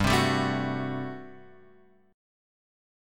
GM7sus2sus4 chord {3 3 4 5 3 5} chord